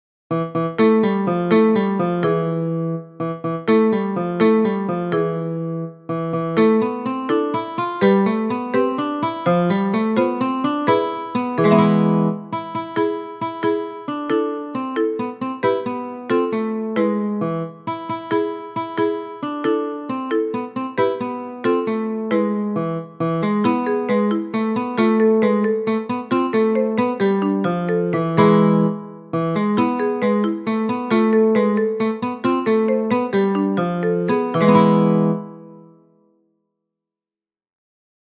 We’re going to practise El Vito using castanets, one of the traditional instruments of Andalusian folk music. Have a look at the score and listen to the accompaning sound.